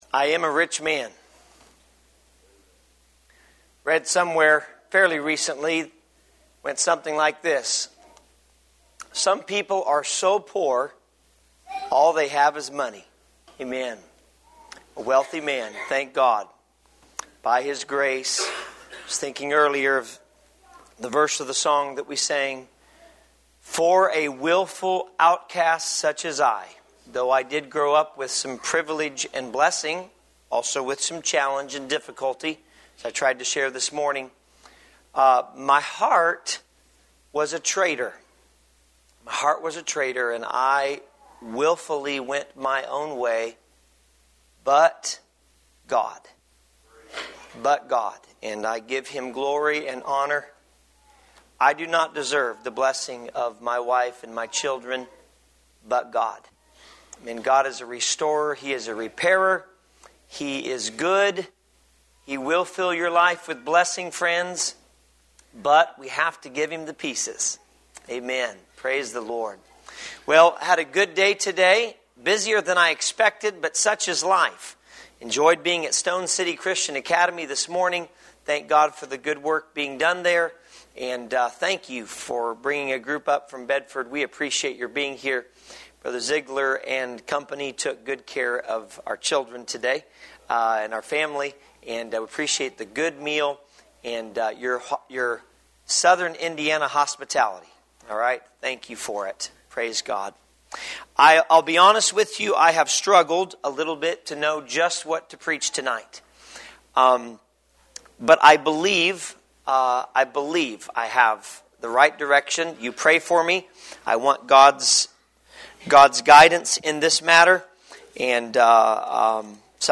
Save Audio A sermon